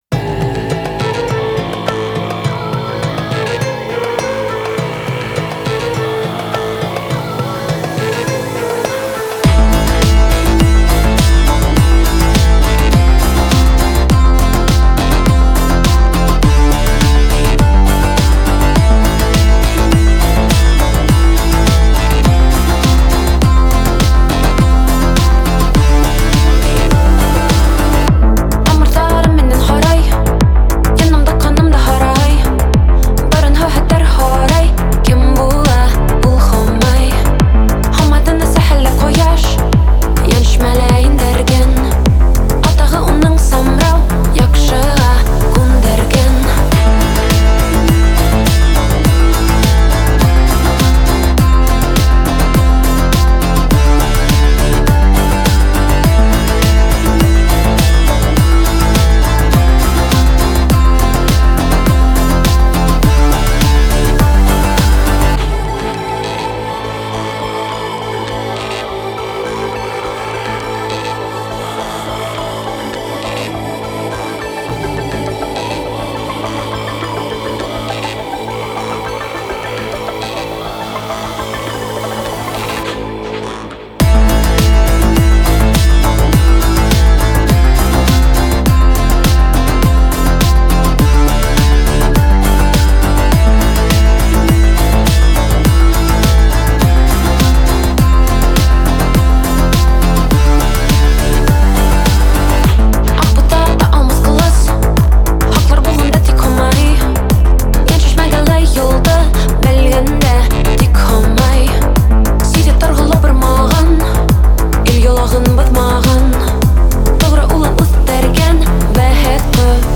• Категория: Детские песни
башкирская песня